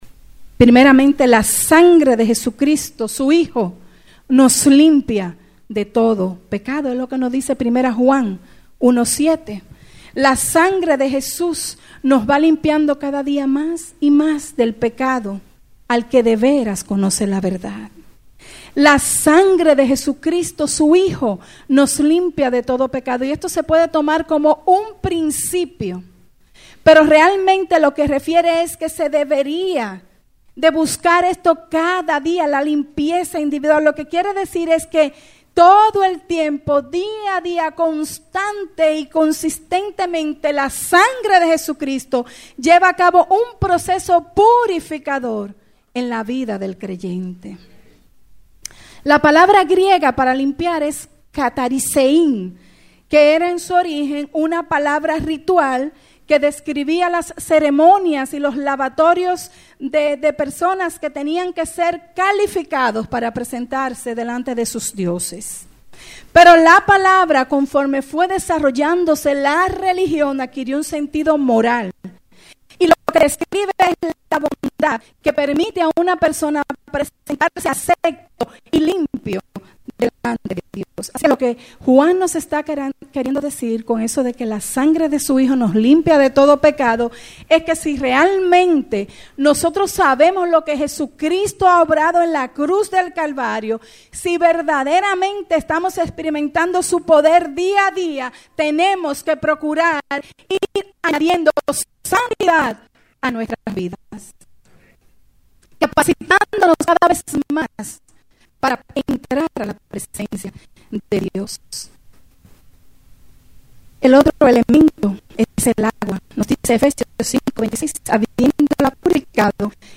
CULTOS – 2015 Colección de archivos de audio correspondientes a los cultos celebrados en la Iglesia Cristiana Metodista ‘Casa de Paz’ en el templo ubicado en Santo Domingo, República Dominicana.